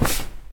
sfx_sit.ogg